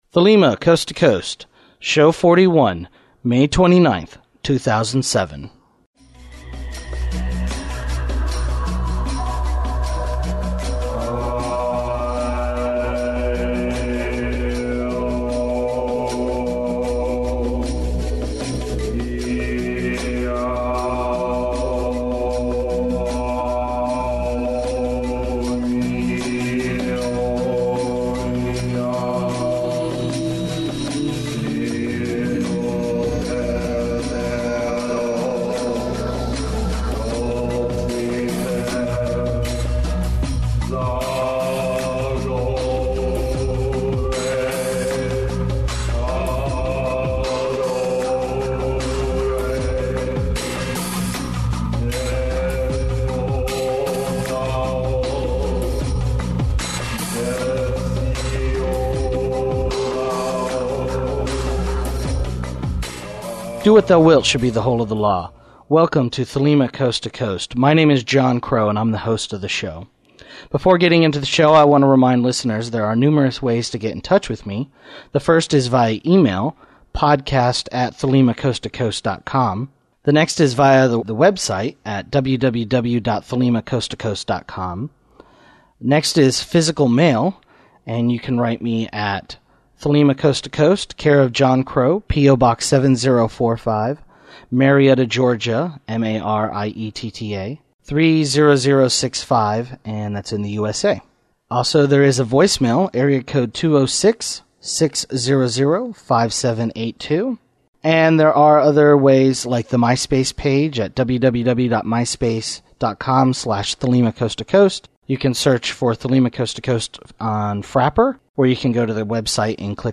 Listener feedback